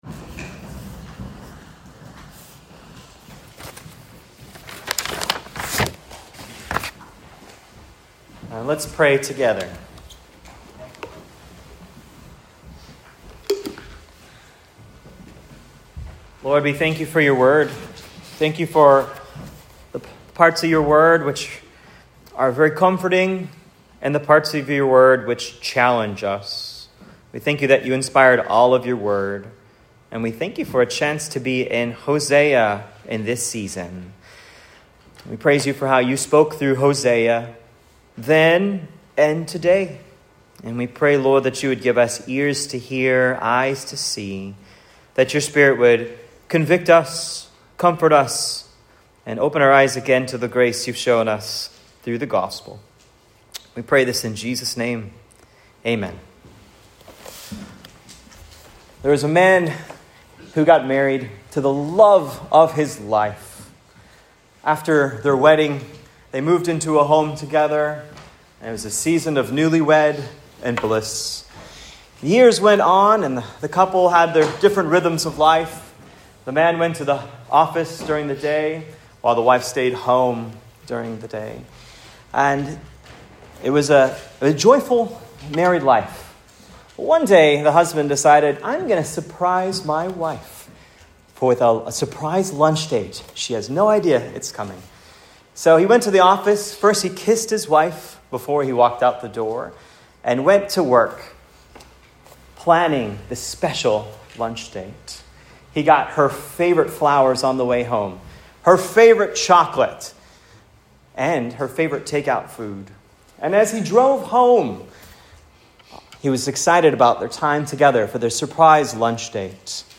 Hosea 2-3 Sermon: Spiritual Adultery and Shocking Grace
(Preached at Cross of Christ Fellowship, Naperville IL. on 5.4.25)